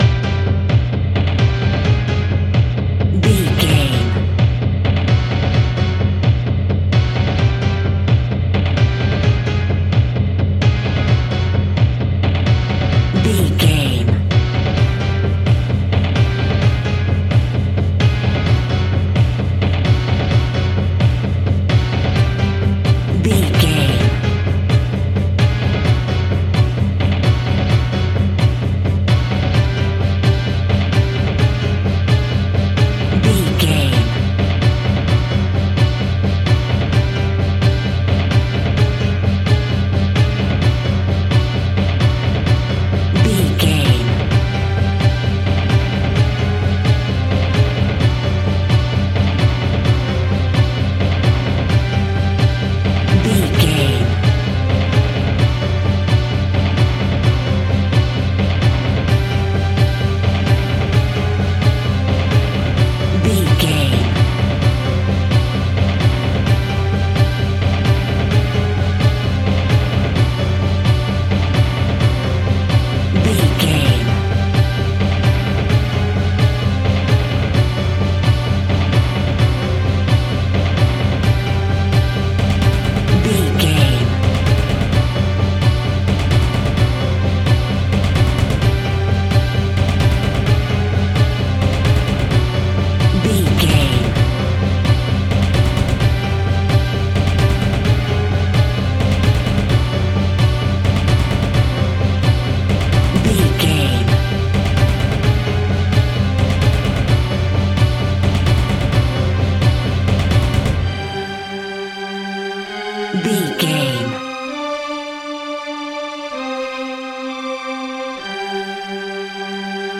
In-crescendo
Aeolian/Minor
Fast
tension
ominous
dark
suspense
haunting
eerie
strings
synth
keyboards
pads
eletronic